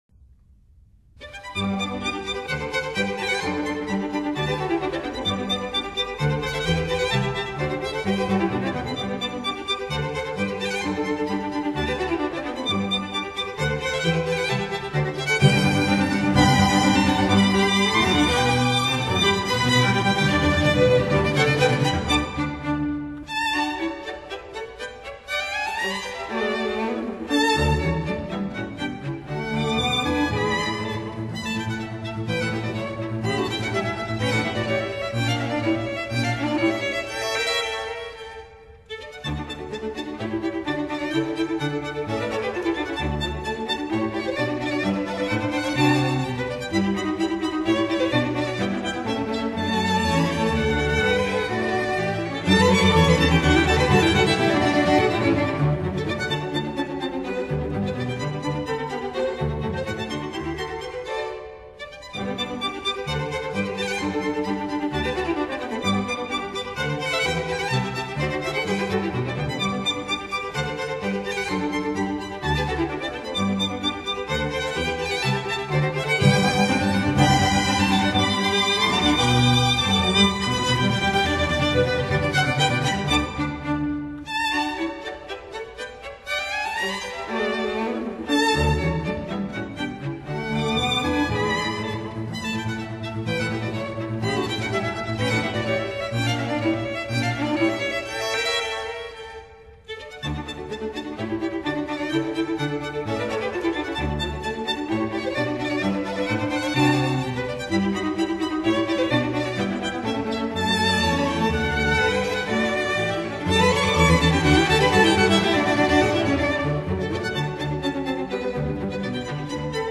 for string quartet & double bass
for 2 horns & string quartet
violin
viola
cello
double bass
horn